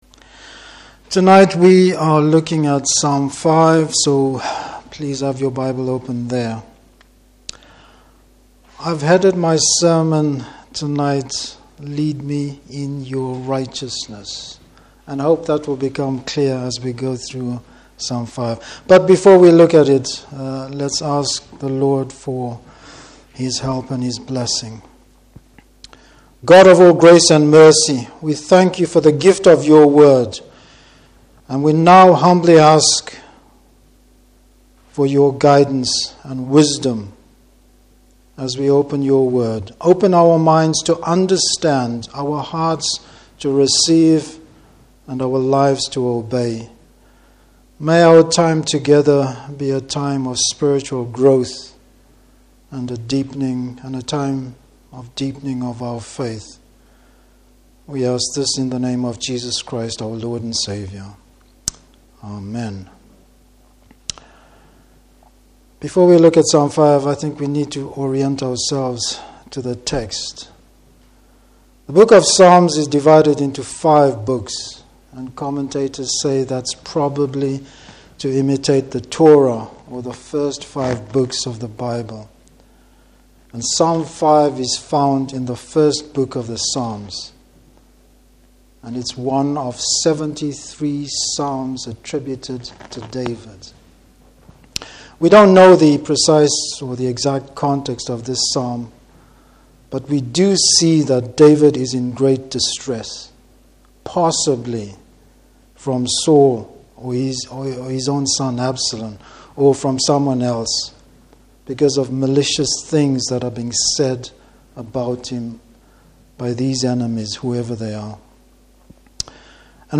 Service Type: Evening Service What the righteous and just nature of God actually means for those who trust Him and those that don’t!